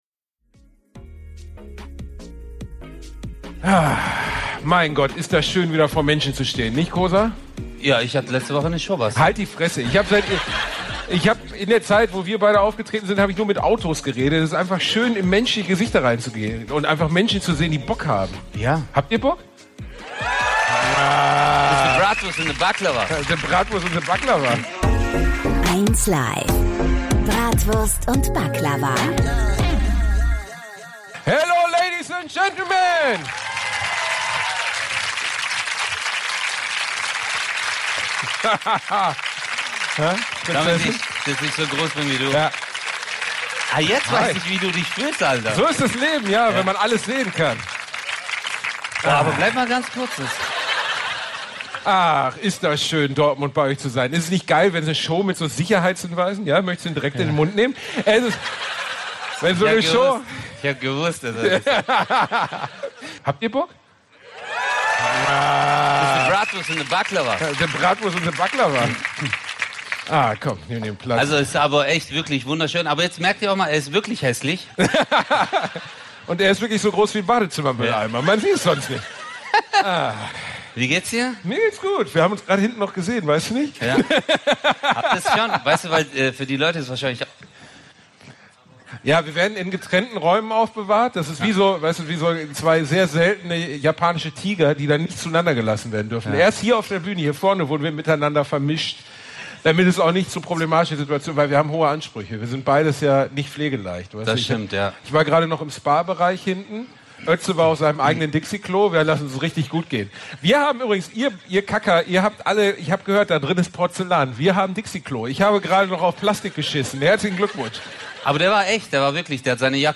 #58 Kein Safeword - live in Dortmund Teil 1 ~ Bratwurst und Baklava - mit Özcan Cosar und Bastian Bielendorfer Podcast